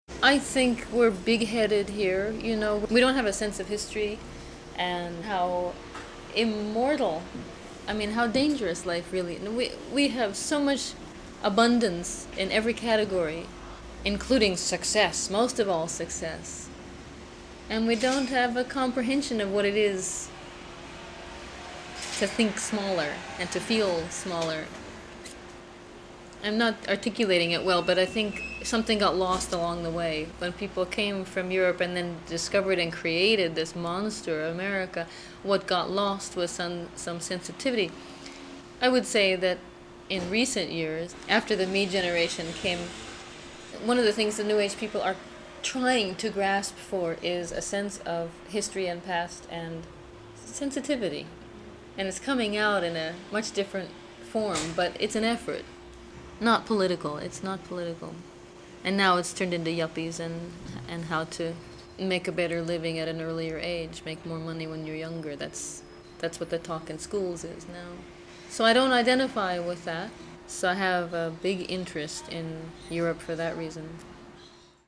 INTERVIEWS WITH MIMI:
for German public radio